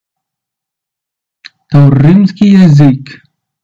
Pronunciation [Tawpʲɪɪmskɪj jɪˈzɨk]
Pronunciation_of_TY.ogg